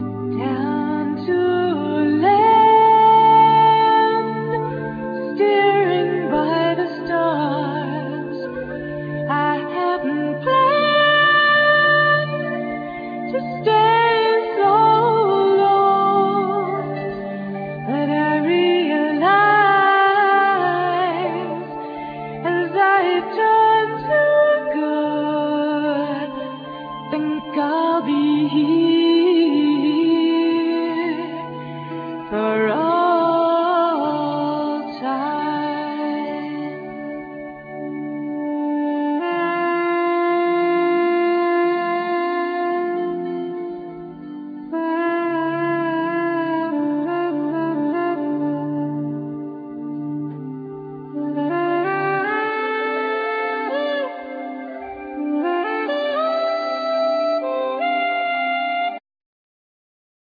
Vocal,Alto saxophone
Ac.guitar,Mandolin
Bouzuki,Bodhran,Coros
Tenor&Soprano saxophone,Synthesizer
Contra-bass,El.bass
Piano,Synthesizer
Drums,Percussions
Trumpet
Irish harp
Irish gaita,Flute